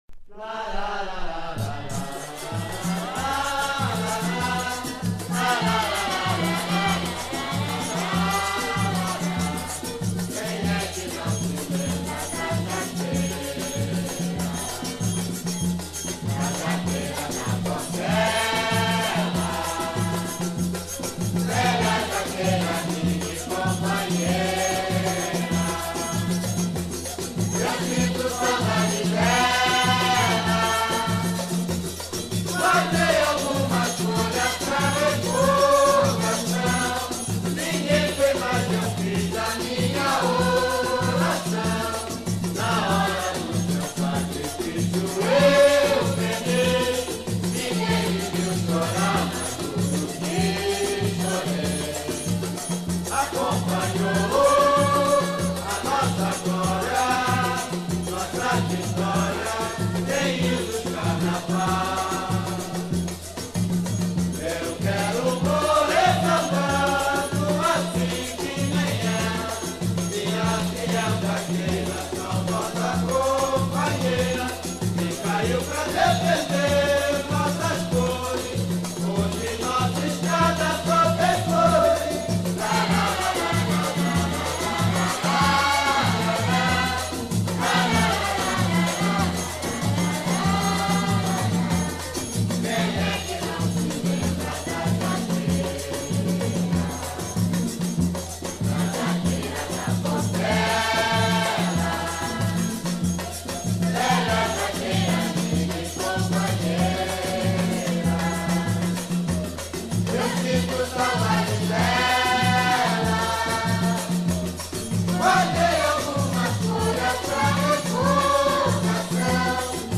samba
Ao desenrolar do tema, entra o côro de pastoras.